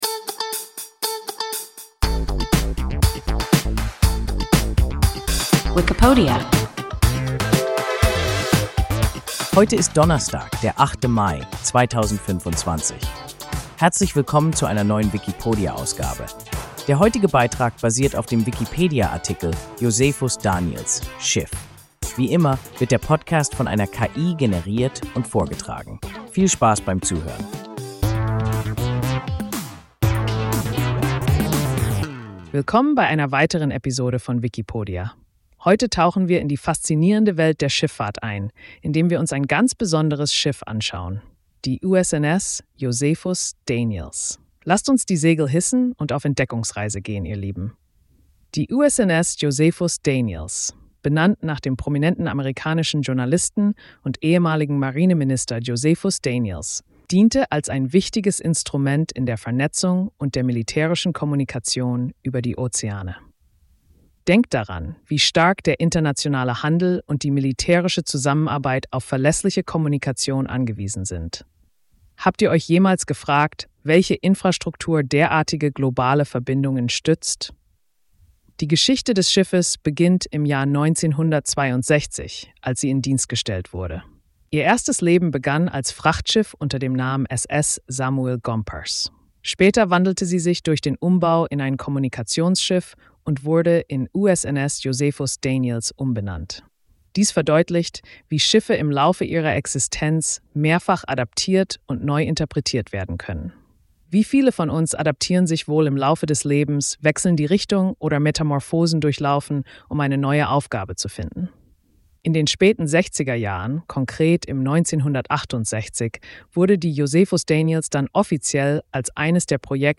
Josephus Daniels (Schiff) – WIKIPODIA – ein KI Podcast